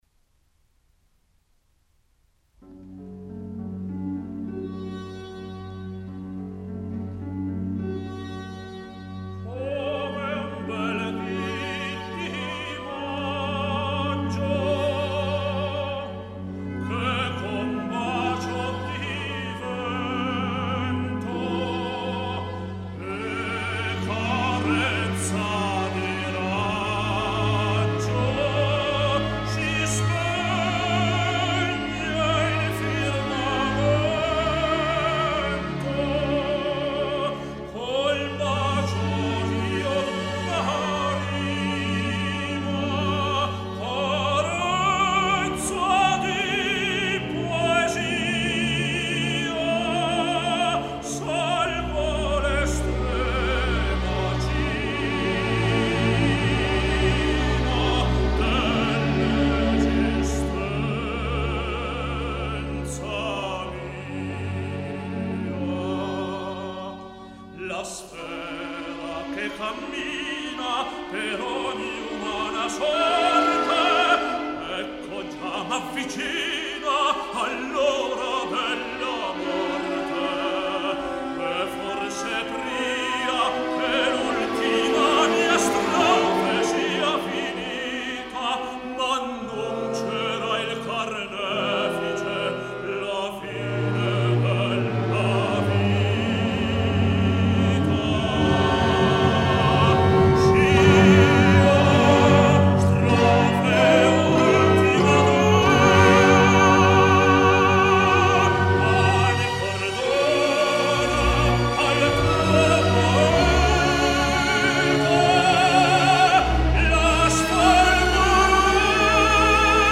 Сегодня исполняется 87 лет великому итальянскому тенору Карло Бергонци!